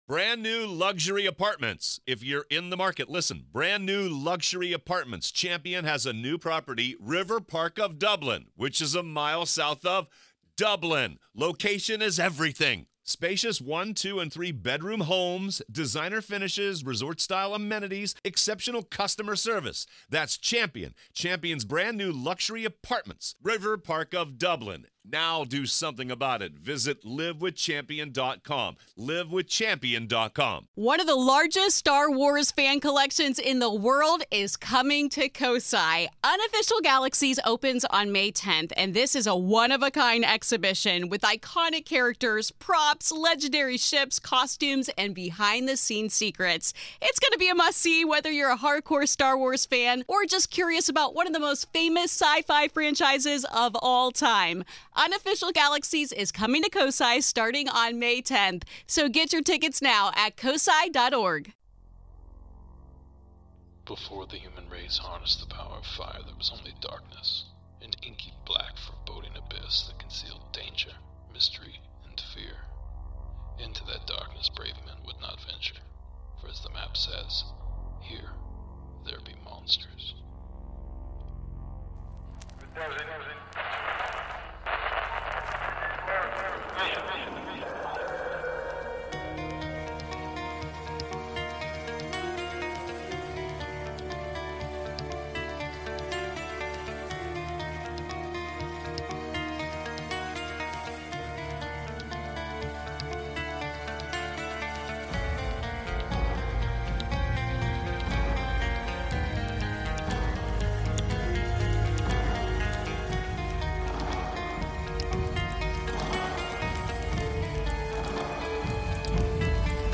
Tonight I play a pair of interviews of two men, each claiming to have shot a Sasquatch.